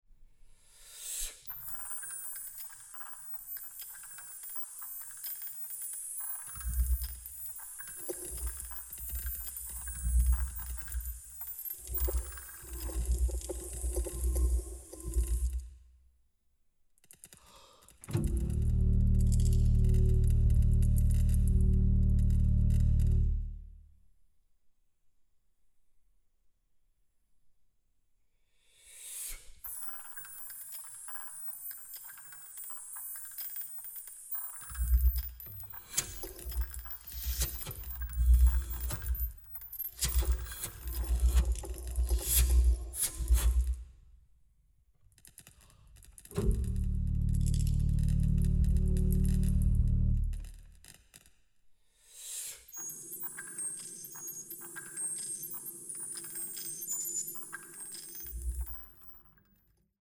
Flöte
Aufnahme: Deutschlandfunk Kammermusiksaal, Köln, 2023 + 2024